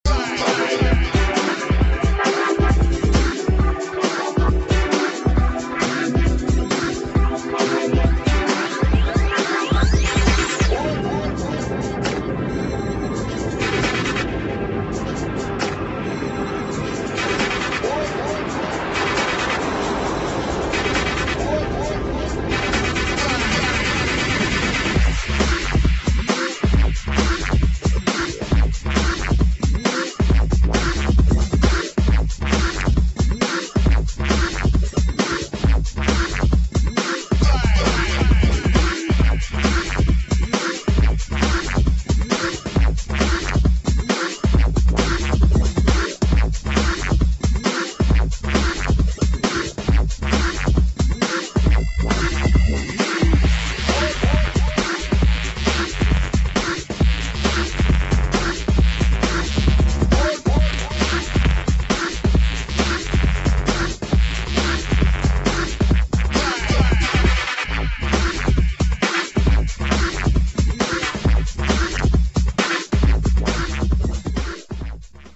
[ BREAKS ]